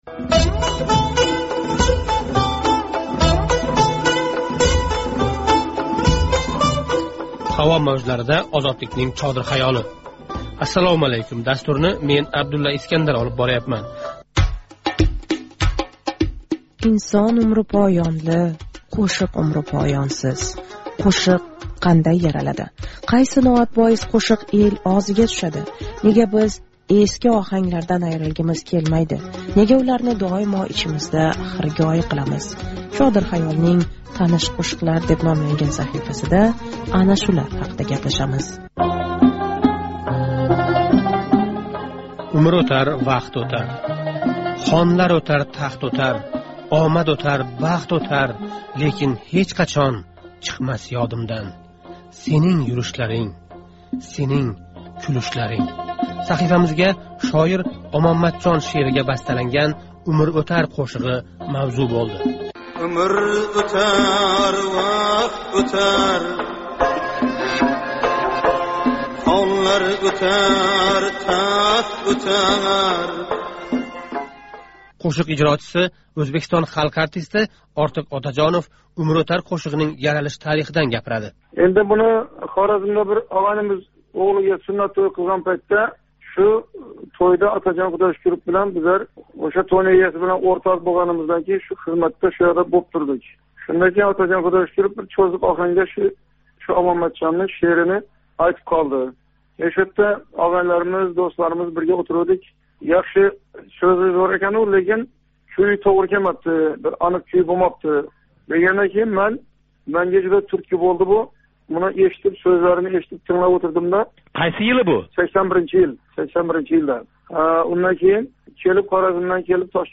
Ортиқ Отажонов билан суҳбат